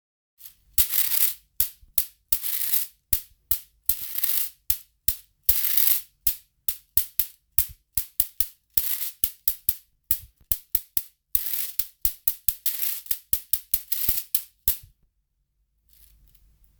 カイヤンバ小
農耕文化の匂いがする、ほうき形の楽器。手のひらに打ち付けて演奏します。
アジアテイストがかすかに残る、マダガスカルならではの楽器です。
素材： 水草 竹